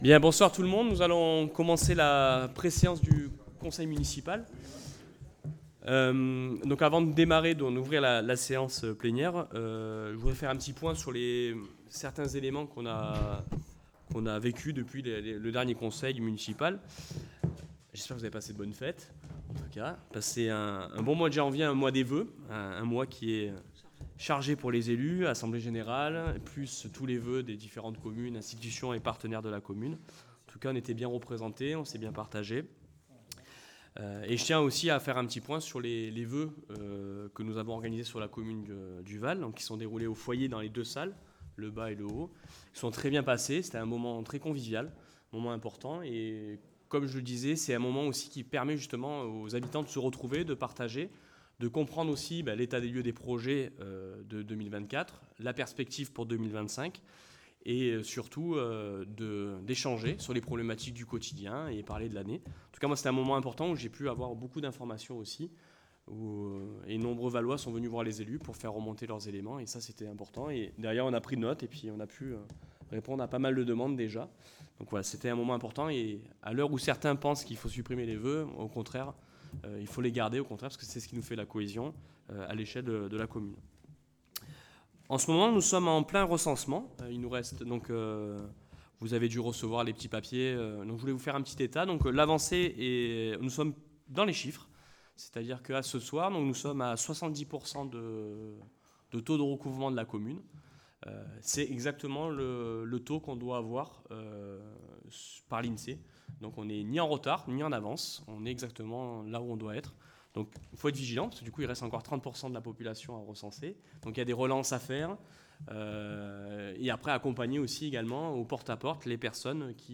Les Conseils Municipaux